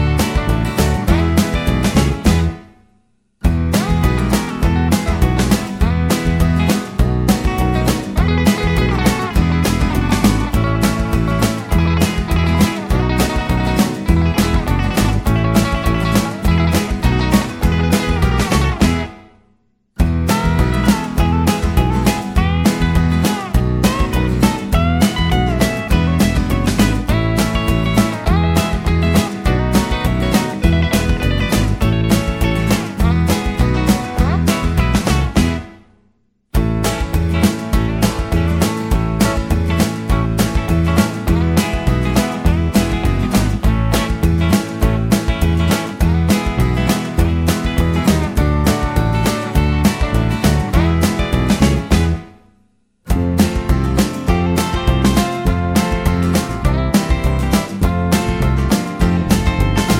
no Backing Vocals Country (Male) 2:40 Buy £1.50